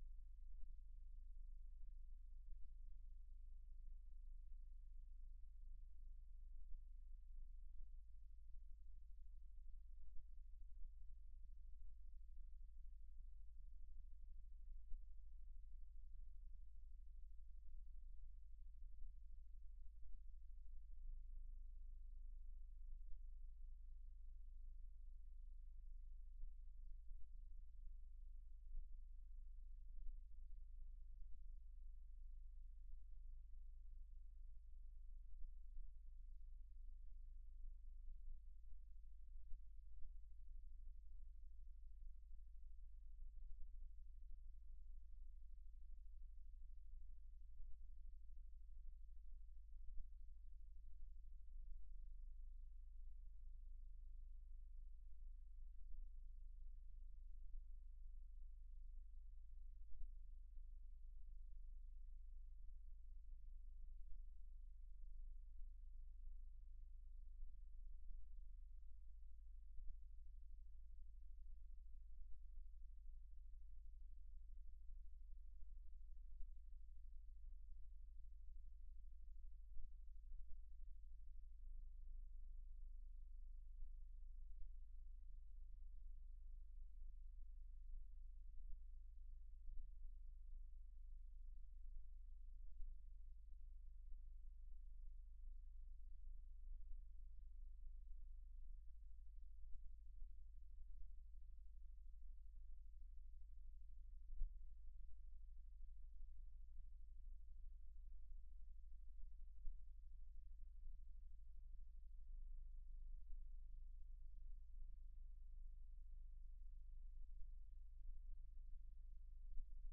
Geomagnetic pulsations PC1
Station 05 - NORTHERN CROSS RADIOTELESCOPE From Medicina (IT - Bologna), N Italy Local noise (in red square) precedes the same pulsation, here detected in Emilia Romagna with ULFO induction coil.
Station 05 - NORTHERN CROSS RADIOTELESCOPE From Medicina (IT - Bologna), N Italy Signal detected with ULFO induction coil.